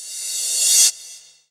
43_02_revcymbal.wav